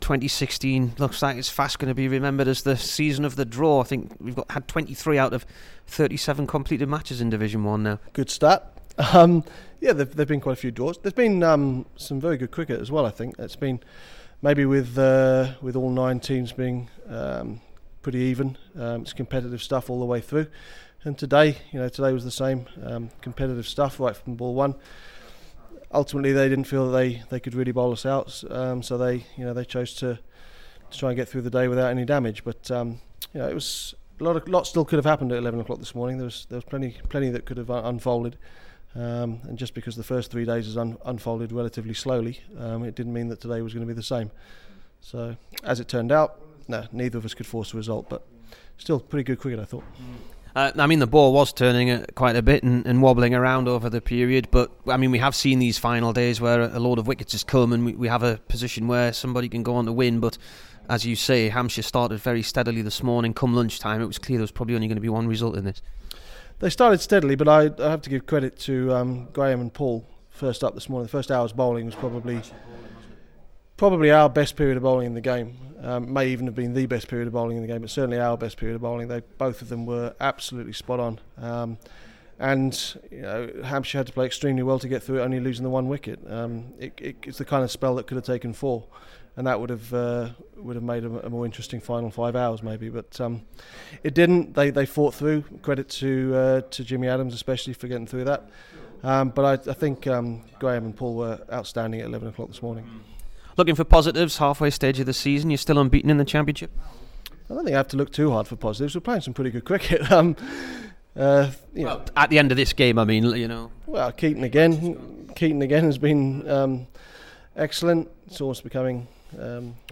JON LEWIS INT
HERE IS THE DURHAM COACH FOLLOWING THE DRAW WITH HANTS IN CLS.